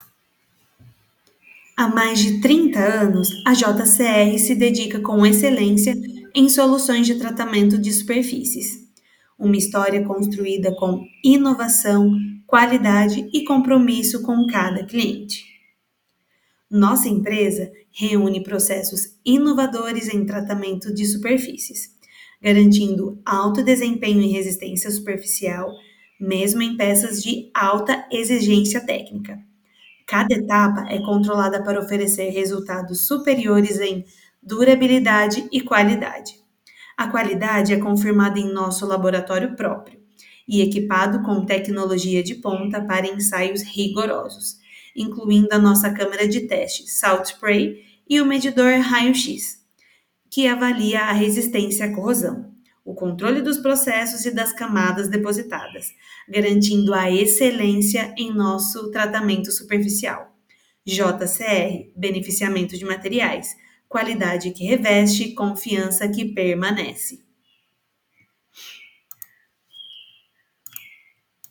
Locução para vídeo institucional de indústria, pode dar destaque nas partes em negrito.